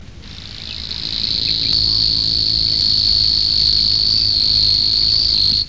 Locustelle luscinioïde
Si vous entendez dans les roseaux un chant tel une stridulation rapide qui ressemble à celle d'une sauterelle, il s'agit d'une locustelle bien cachée dans la roselière, à l'abri des regards. C'est avant tout par son chant qu'on la distingue facilement de la rousserolle.
locustelle.mp3